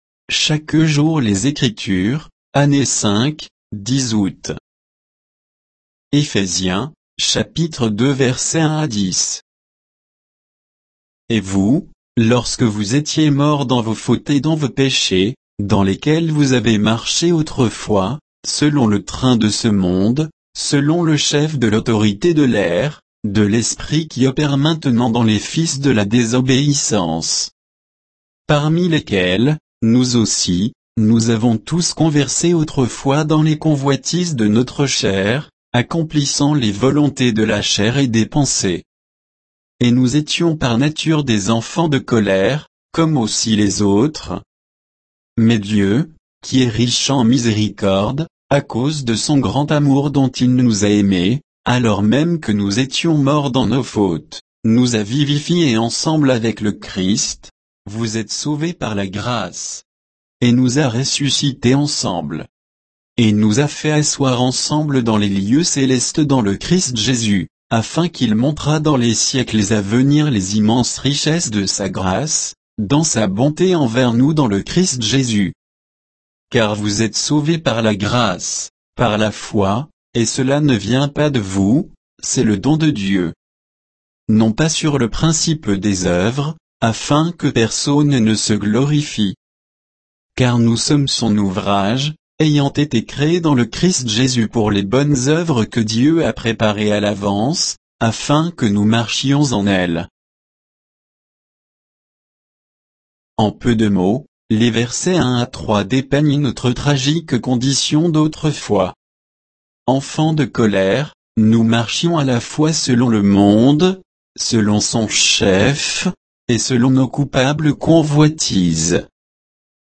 Méditation quoditienne de Chaque jour les Écritures sur Éphésiens 2